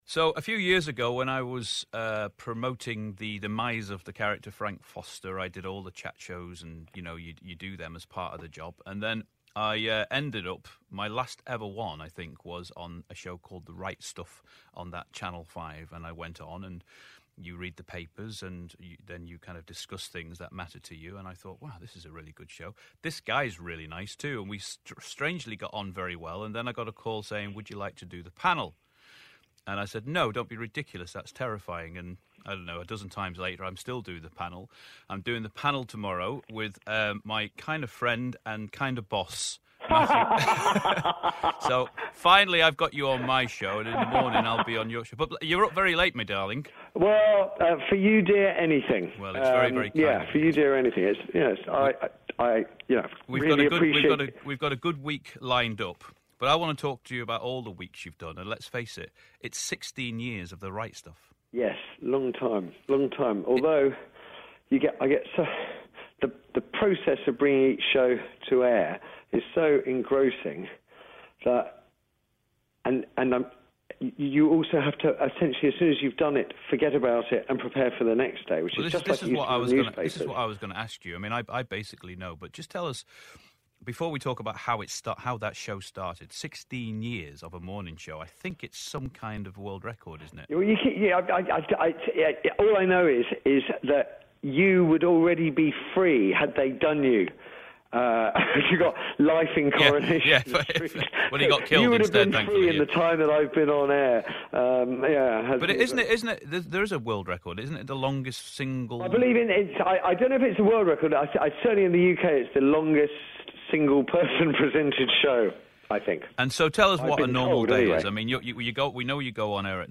Listen again to Andrew Lancel chatting to TV host Matthew Wright on 'Late Night City' on Radio City 2 & Radio City Talk